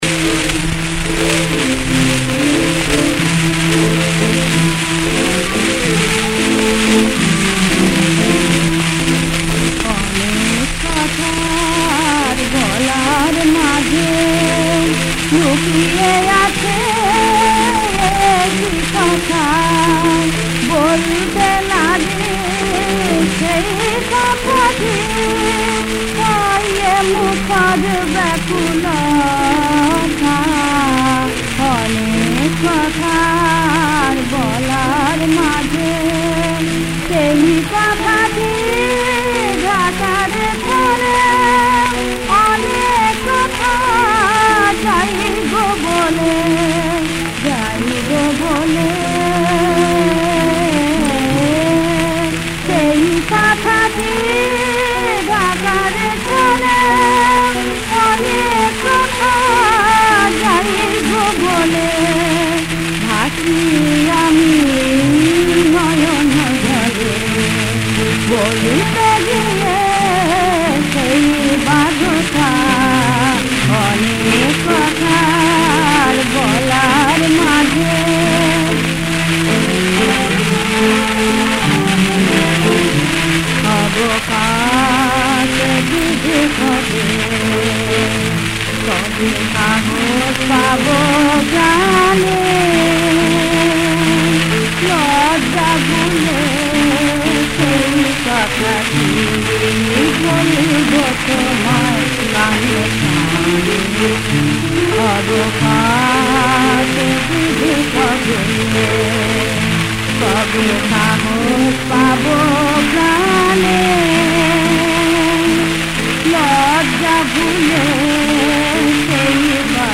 • তাল: কাহারবা